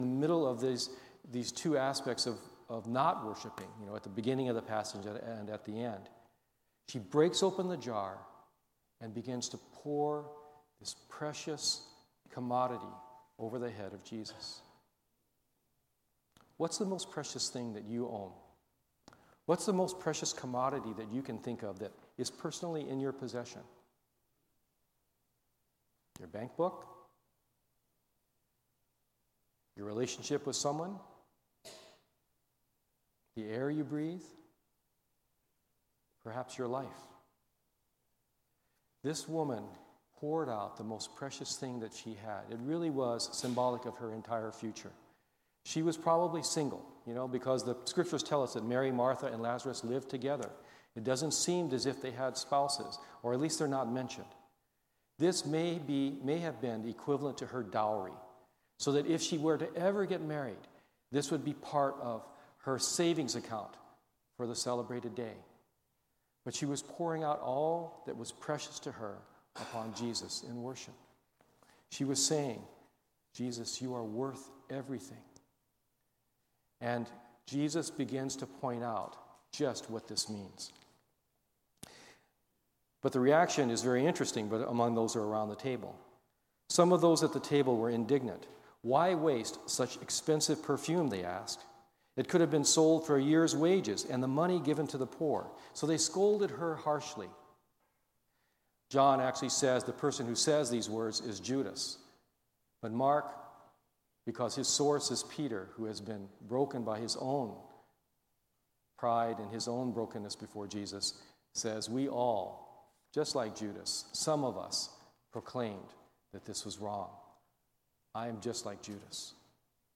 We apologize, but not all of the sermon was able to be uploaded for listening.